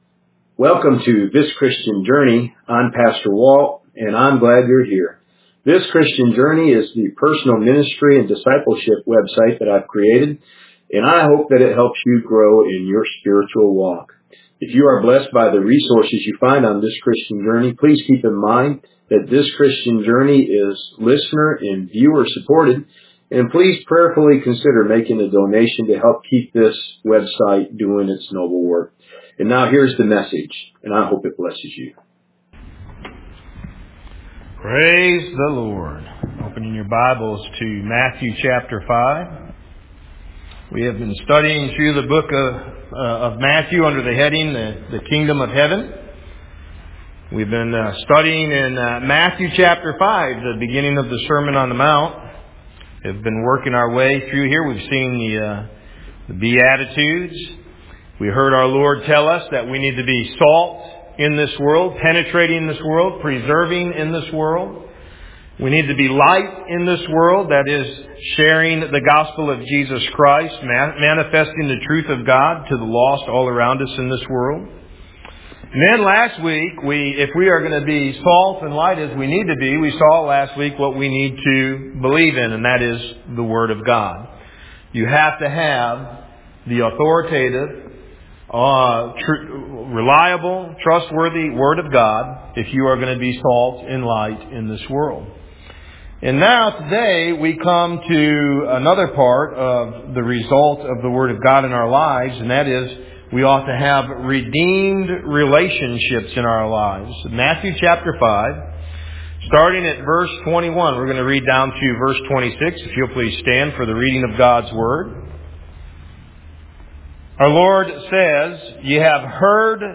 Have Faith In God Audio Sermon and Outline